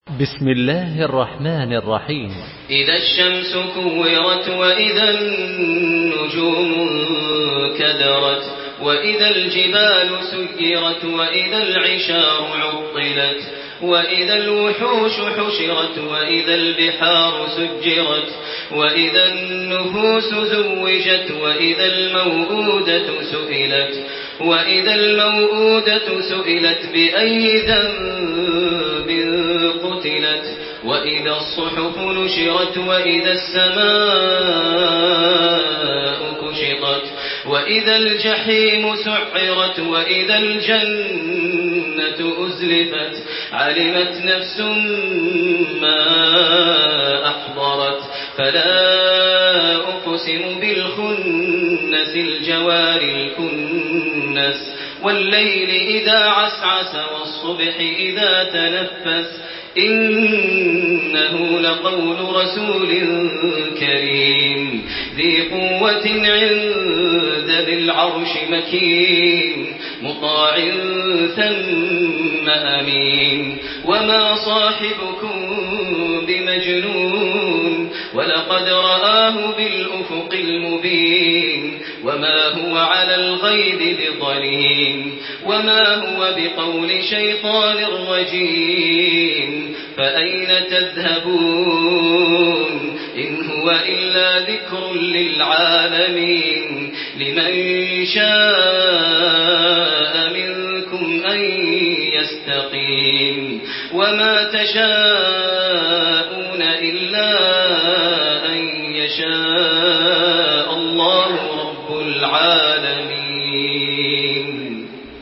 سورة التكوير MP3 بصوت تراويح الحرم المكي 1428 برواية حفص عن عاصم، استمع وحمّل التلاوة كاملة بصيغة MP3 عبر روابط مباشرة وسريعة على الجوال، مع إمكانية التحميل بجودات متعددة.
تحميل سورة التكوير بصوت تراويح الحرم المكي 1428